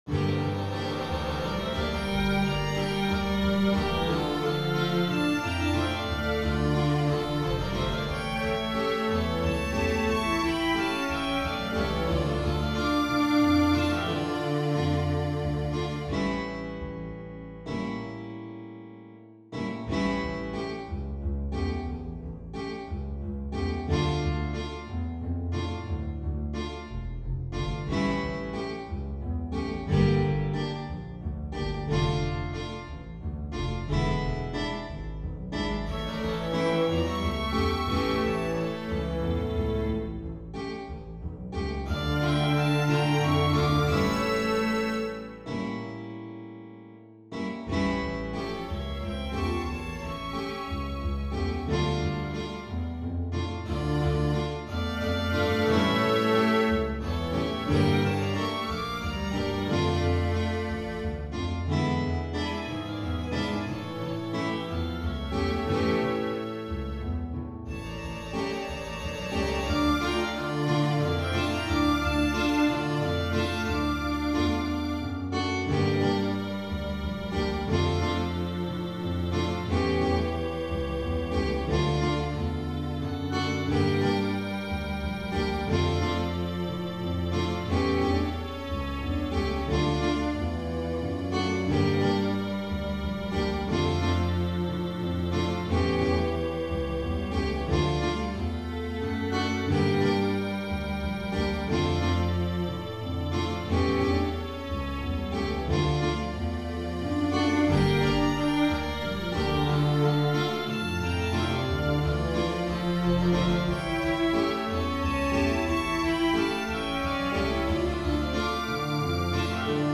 Pasillo
mp3 Audio accompanimiento  | Violin 1  |